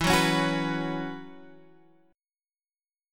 Bsus2/E chord